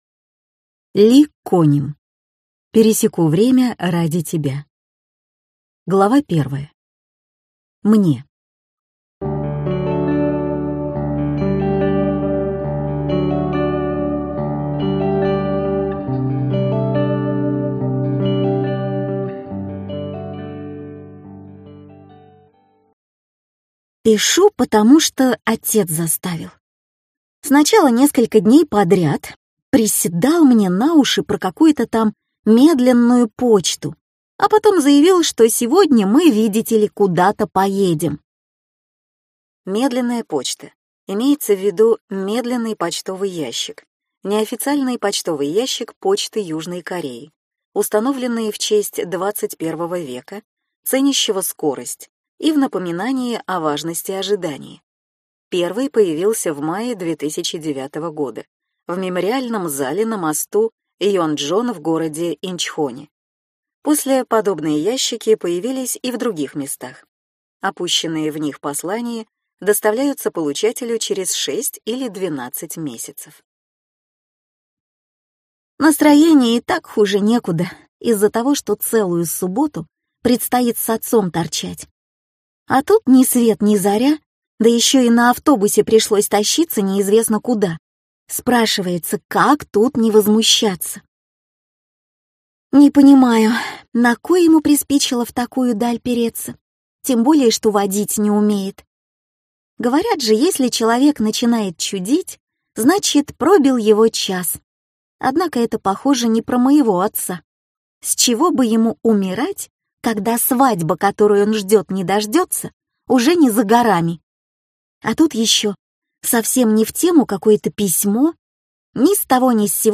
Аудиокнига Пересеку время ради тебя | Библиотека аудиокниг